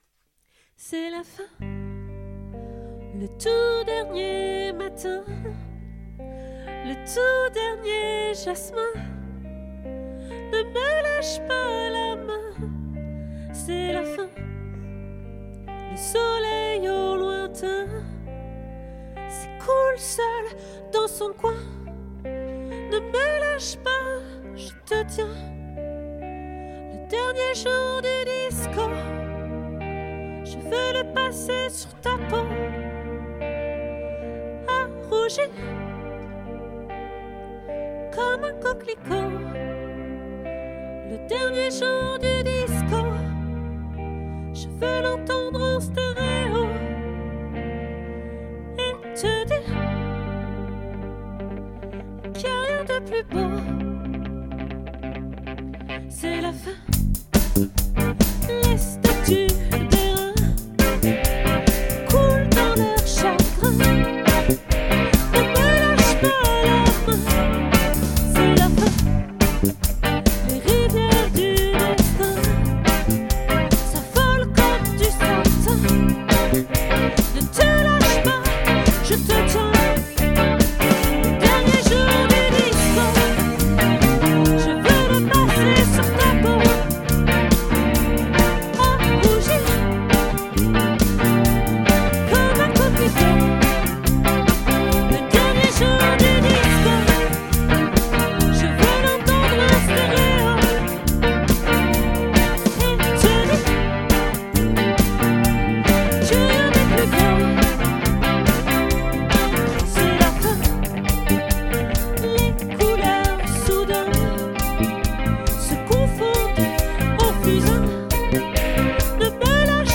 🏠 Accueil Repetitions Records_2024_01_24